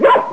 bark.mp3